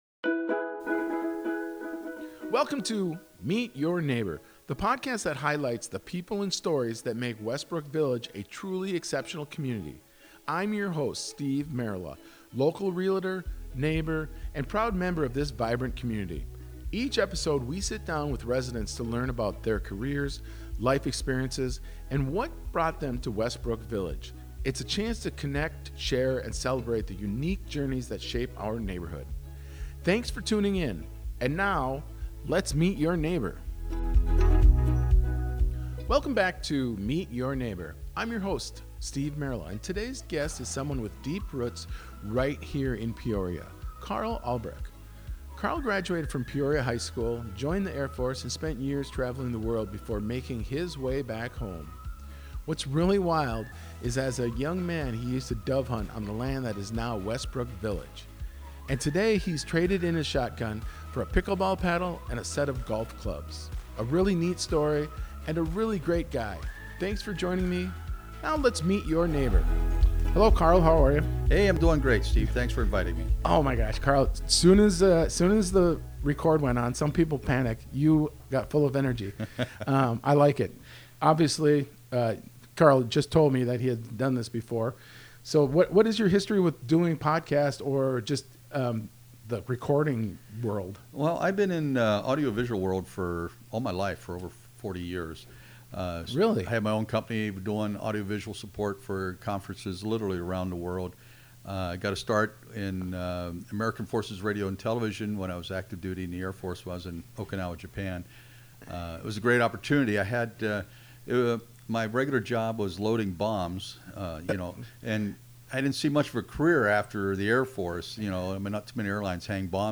It’s a powerful, inspiring conversation — and I’m honored to share it with you.